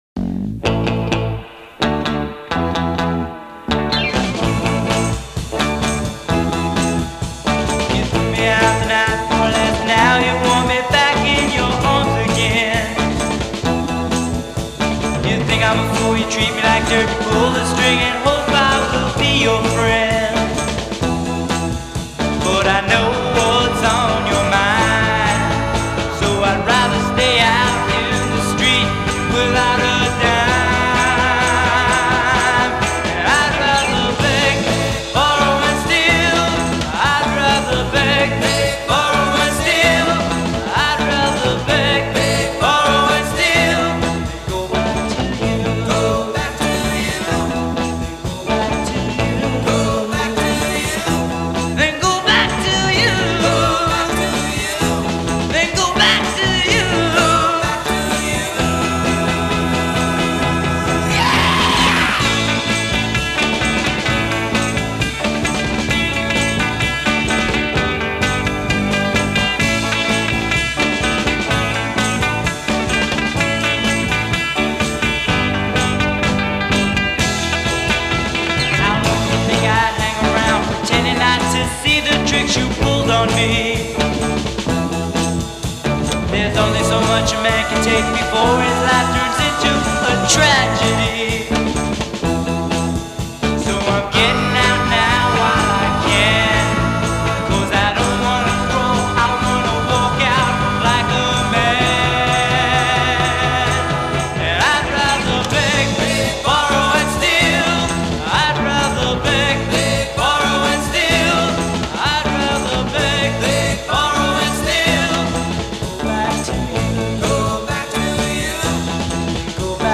comes much closer to garage rock than their bubblegum hits